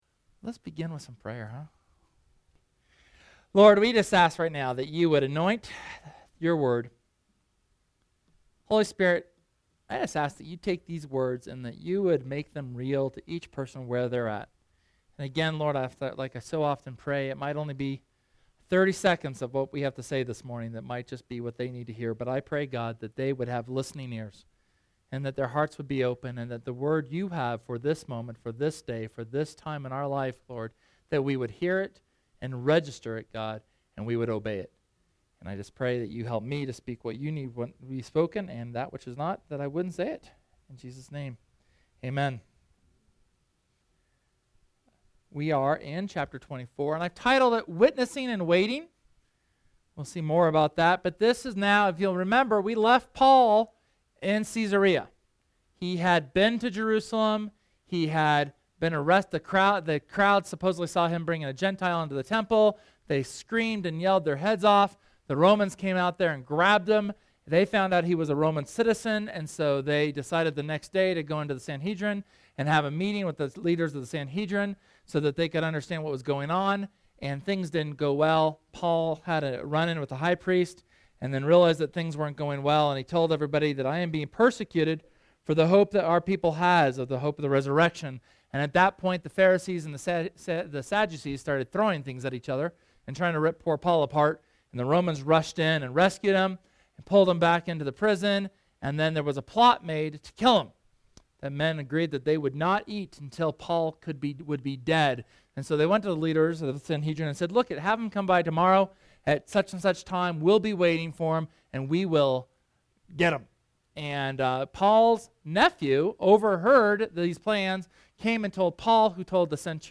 SERMON: Witnessing & Waiting